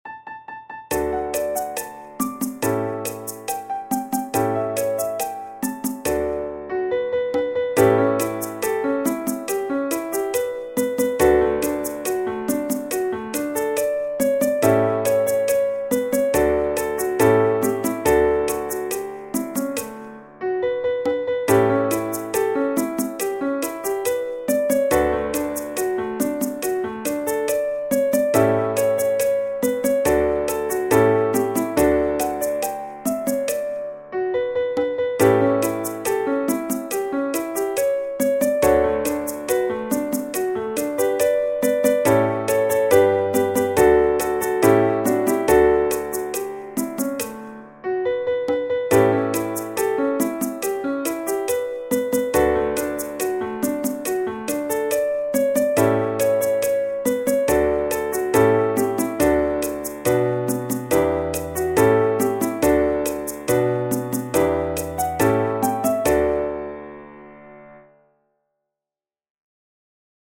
cha cha cha